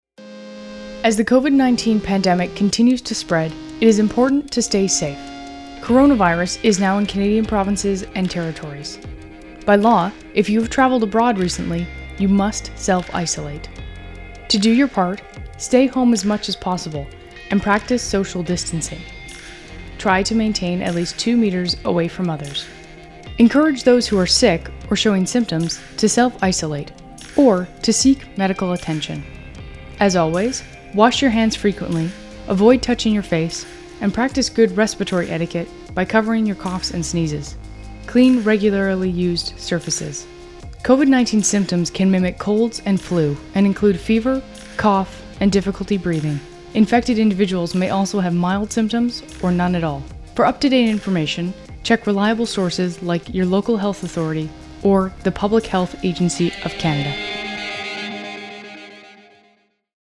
Type: PSA